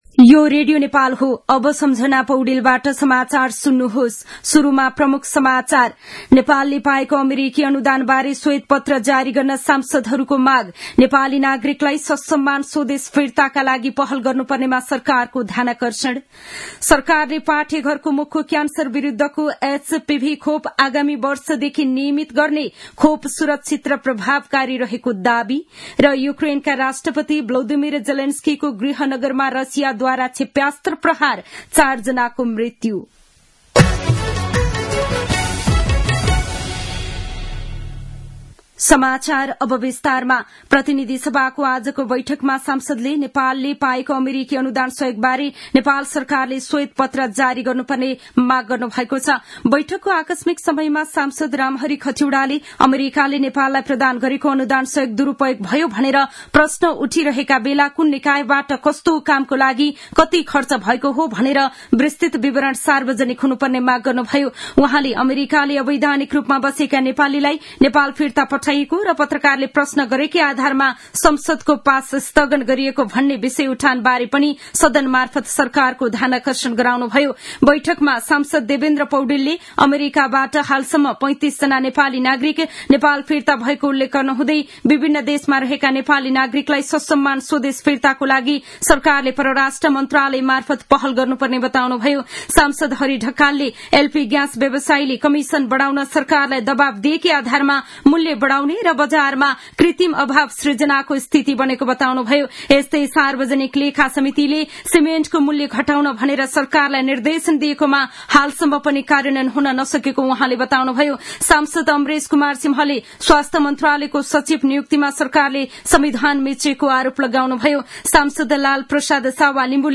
दिउँसो ३ बजेको नेपाली समाचार : २३ फागुन , २०८१
3-pm-nepali-news-.mp3